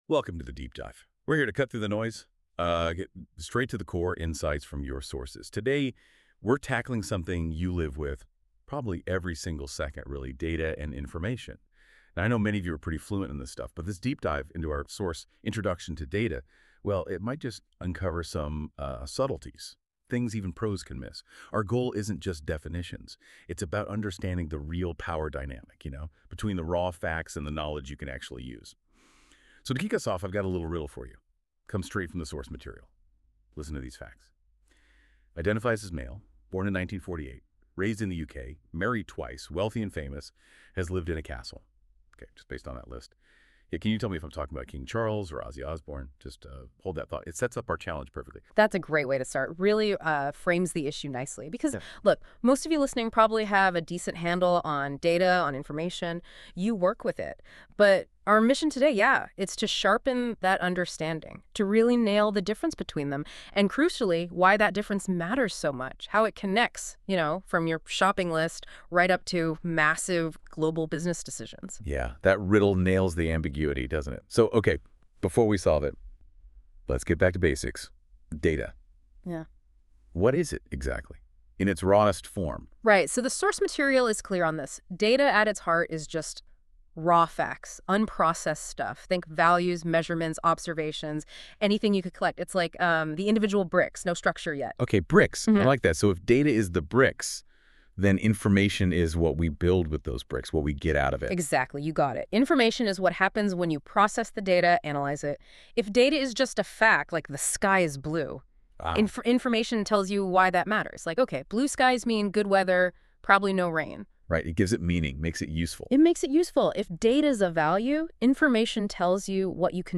Activity: Listen to This Podcast That was created using AI from these materials.